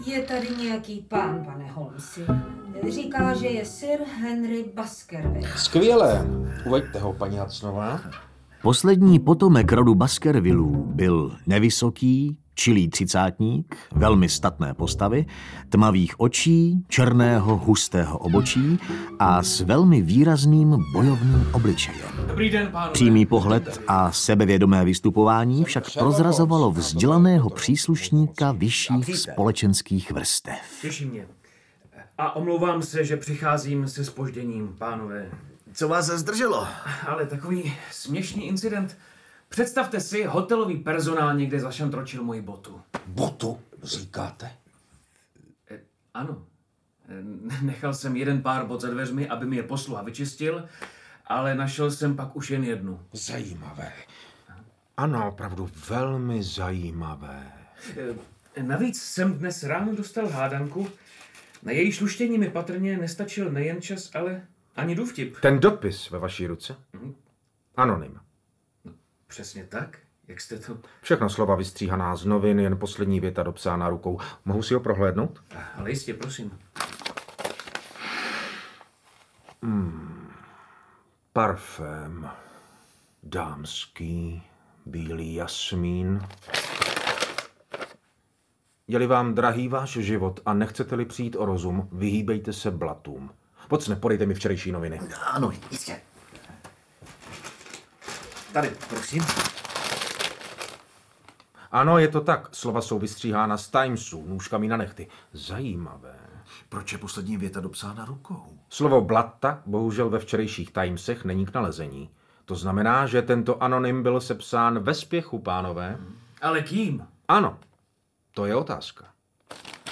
• Rozhlasová nahrávka z roku 2021
Část nahrávky se natáčela v pražském Prokopském údolí